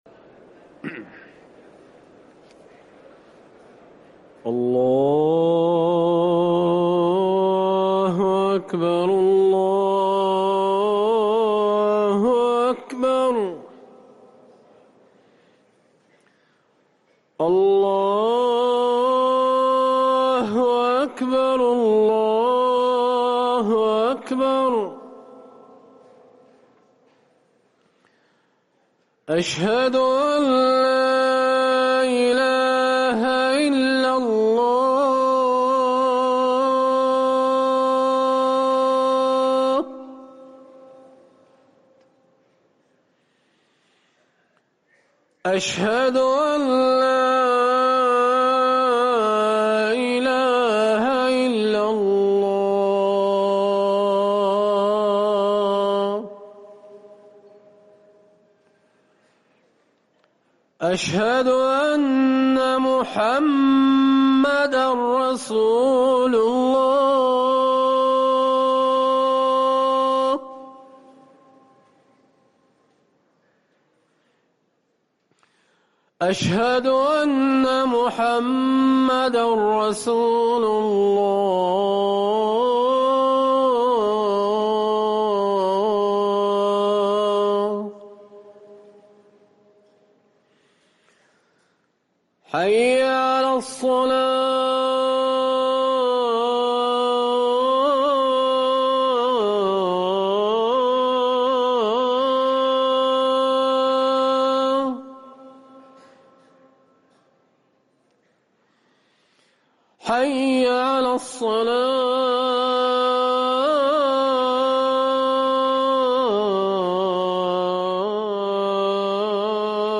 اذان العصر